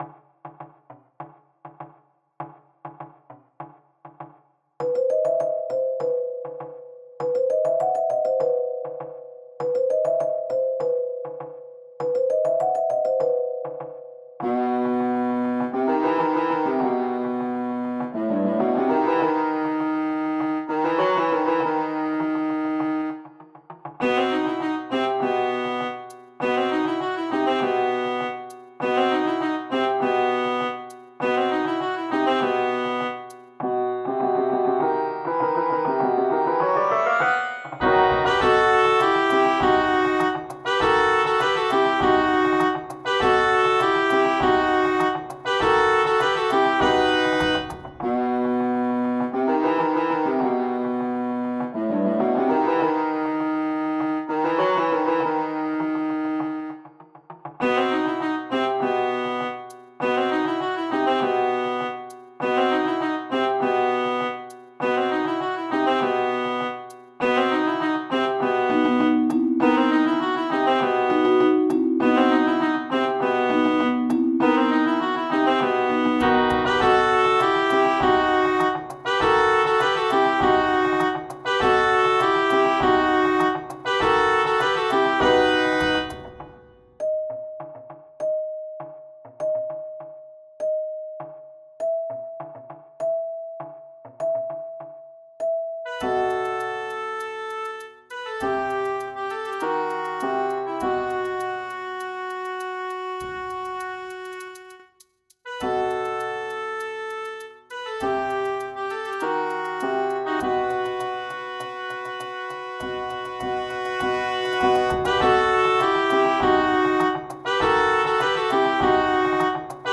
リズミカルで聞きやすいアラビア風BGMです。
アラビア、リズミカル、さわやか